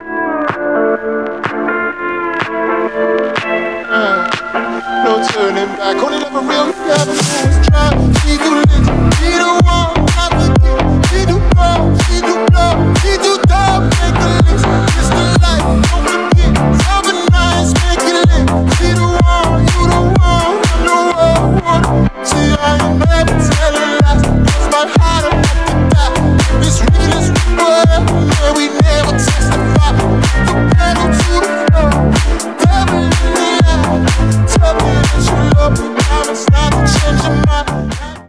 • Качество: 320 kbps, Stereo
Ремикс
клубные